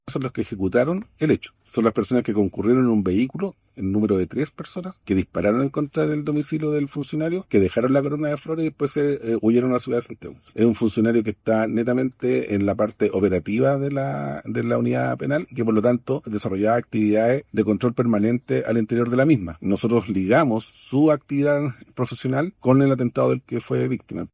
Al respecto, el fiscal metropolitano Occidente, Marcos Pastén, señaló que el funcionario agredido cumple labores clave al interior de la unidad penal. Asimismo, destacó que el trabajo del Ministerio Público permitió vincular a los detenidos con las amenazas y el ataque sufrido por el gendarme y su familia.